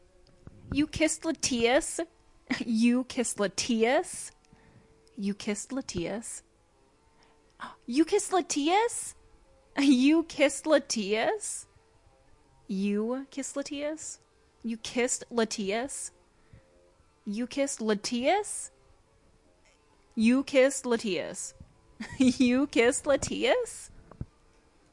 描述：这是我的声音，一个配音。女性是否与另一个角色说话。 它是用USB麦克风和大胆录制的。
Tag: 讲话 谈话 声音 女孩 美国 声乐 请求女人